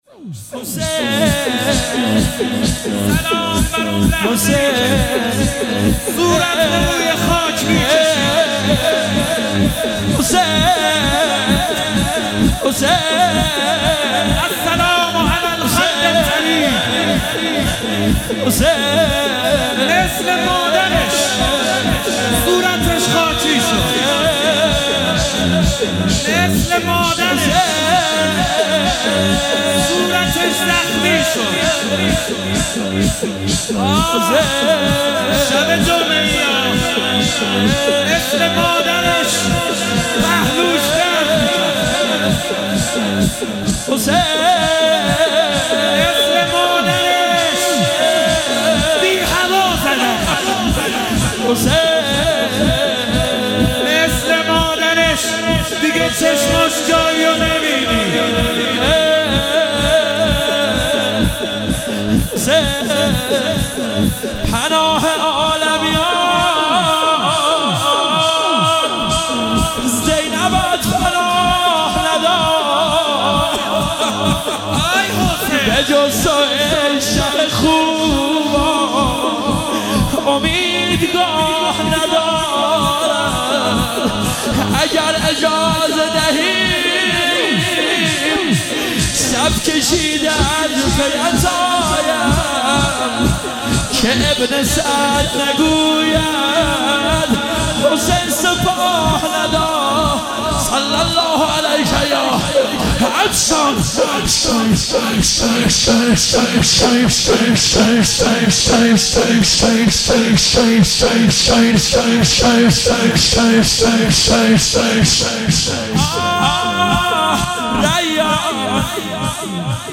مجلس روضه فاطمیه